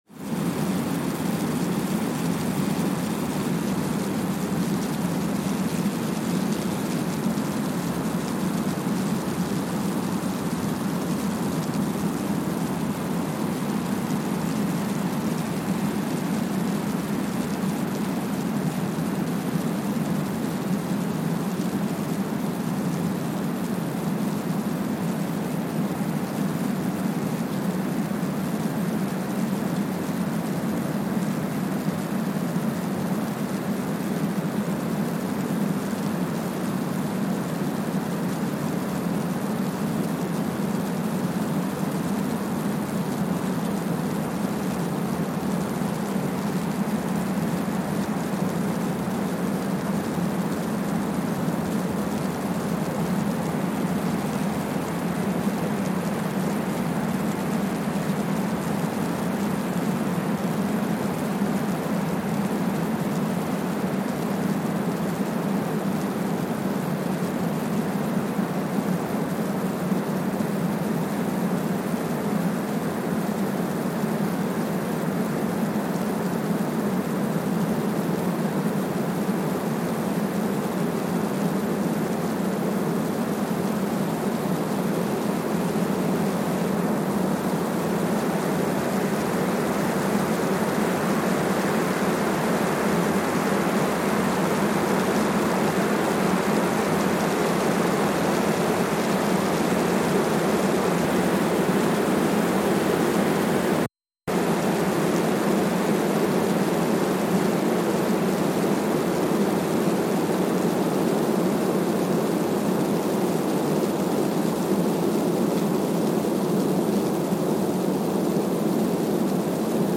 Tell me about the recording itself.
Kwajalein Atoll, Marshall Islands (seismic) archived on October 16, 2020 Sensor : Streckeisen STS-5A Seismometer Speedup : ×1,000 (transposed up about 10 octaves) Loop duration (audio) : 05:45 (stereo) SoX post-processing : highpass -2 90 highpass -2 90